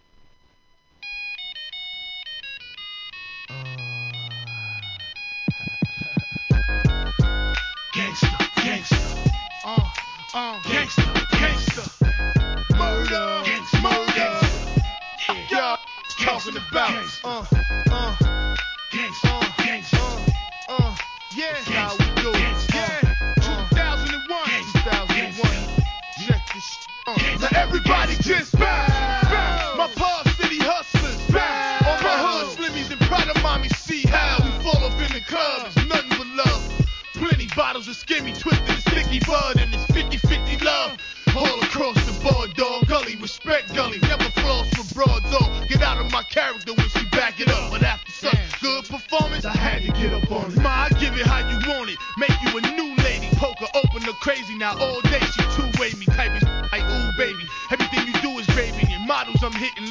HIP HOP/R&B
携帯の着信音のようなメロディーを使用した上音が斬新でした!!